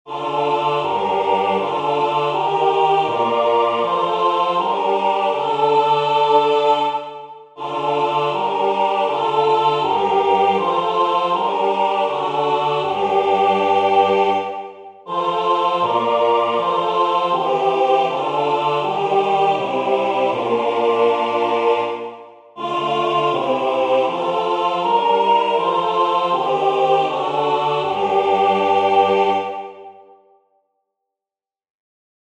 Skaņdarbs lieliski piemērots senās mūzikas praktizēšanai, visas balsis dzied vienā ritmā. Darbs ir četrām balsīm – skatoties no augšas tās ir Superius, Tenors, Kontratenors un Bass.